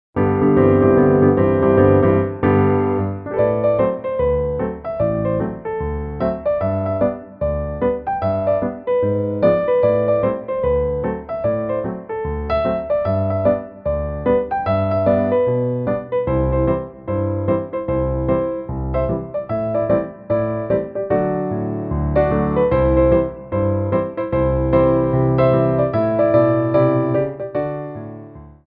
Medium Allegro 1
4/4 (16x8)